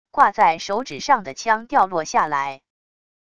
挂在手指上的枪掉落下来wav音频